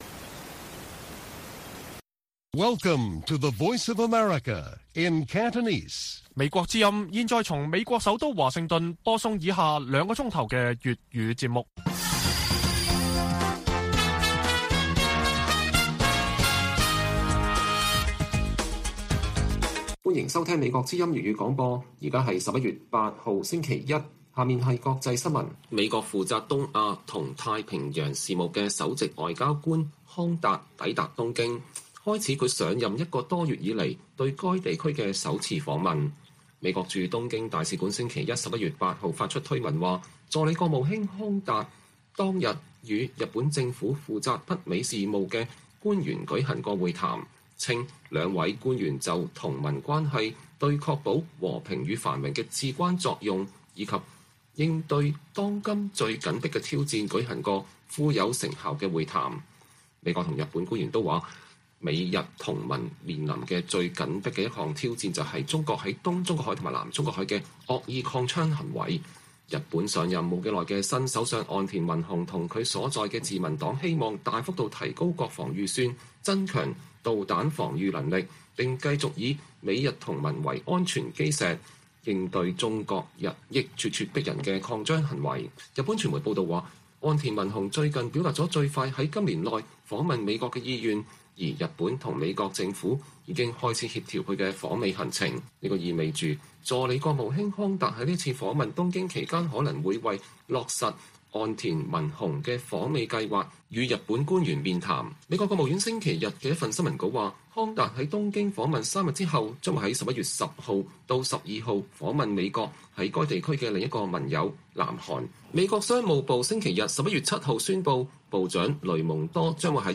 粵語新聞 晚上9-10點: 美國新任東亞事務助卿首次出訪但無訪華安排